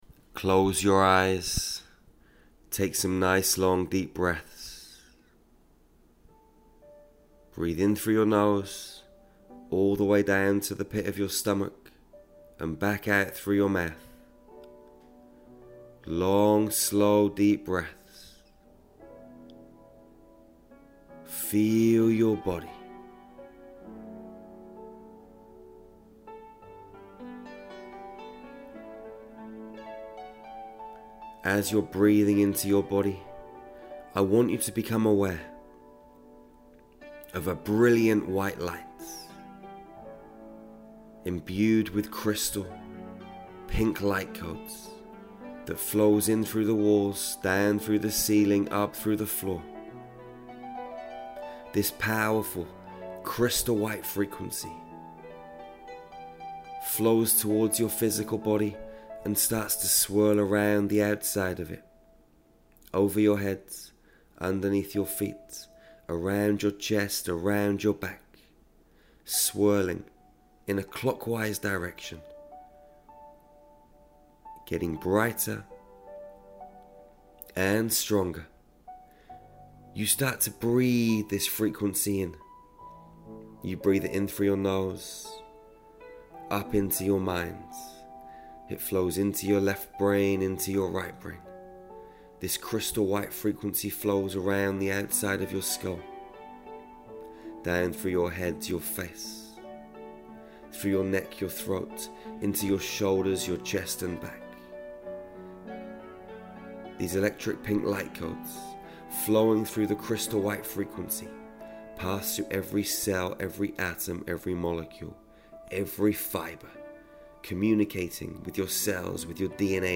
This meditation will take you into a space of peace and calmness. Any heaviness, worry, fear, will simply slide off from and out from your body, it will disappear from your consciousness, as it transmutes into positive energy and pure white light.